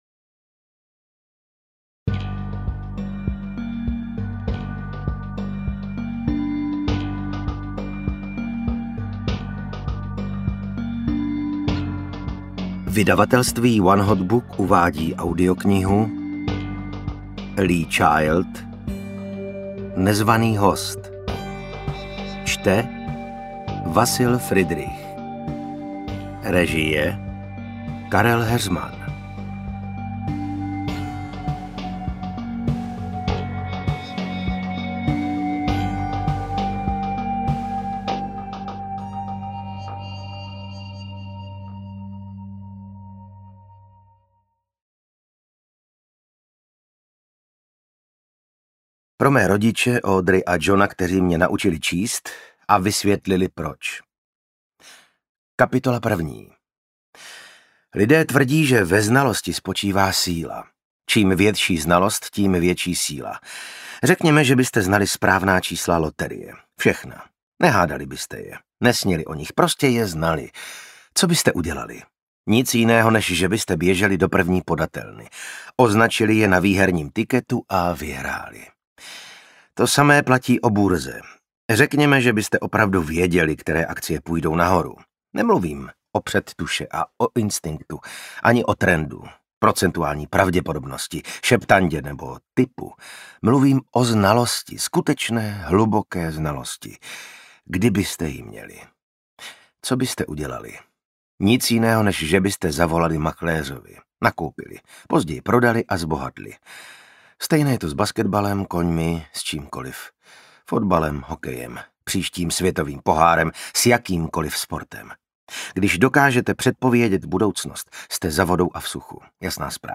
Interpret:  Vasil Fridrich
AudioKniha ke stažení, 38 x mp3, délka 14 hod. 8 min., velikost 776,3 MB, česky